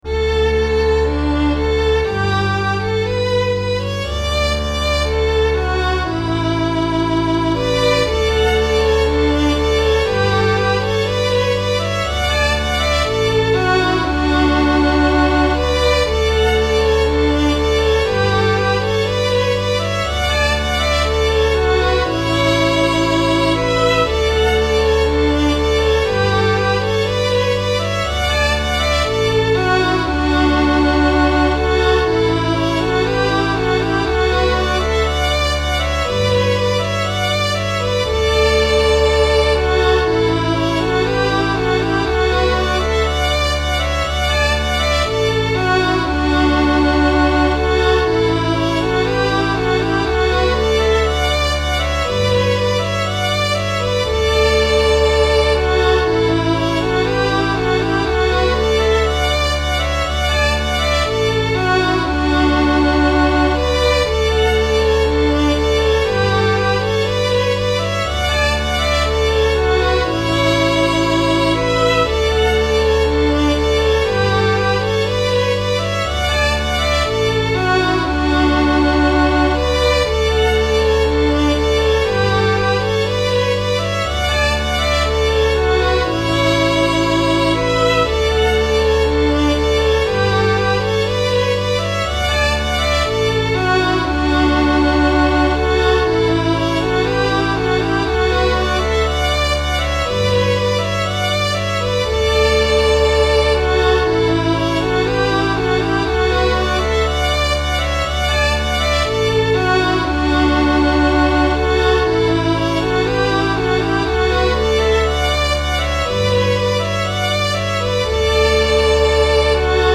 Here's another colonial piece, "On to Yorktown"
I have included parts for 1st and 2nd violin, as well as double bass.  This is a very happy tune and one I really enjoyed writing.